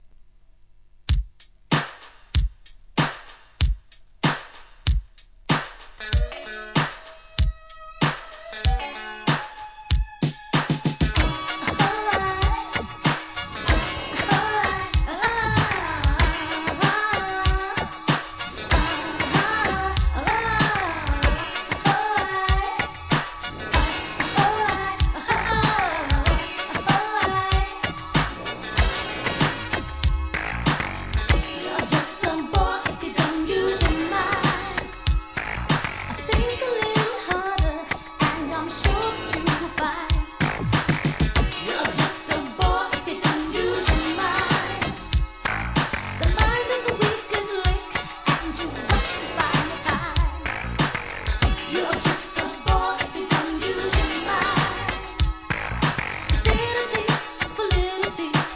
¥ 3,300 税込 関連カテゴリ SOUL/FUNK/etc...